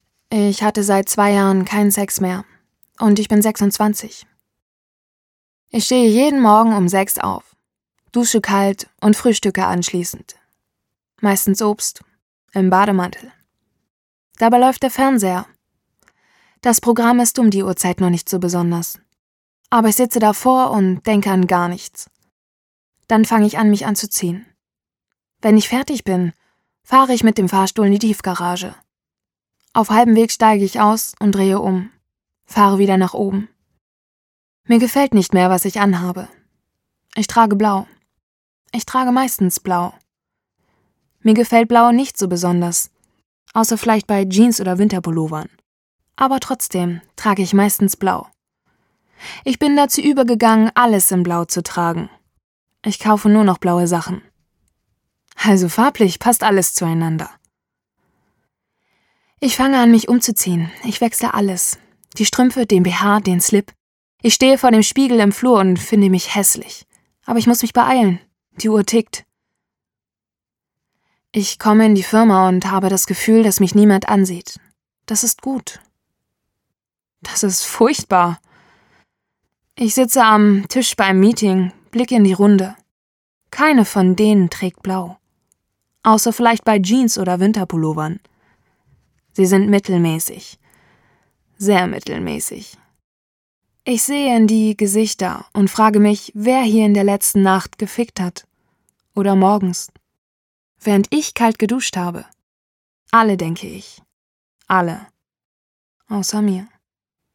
Sprecherin deutsch
norddeutsch
Sprechprobe: eLearning (Muttersprache):
german female voice over artist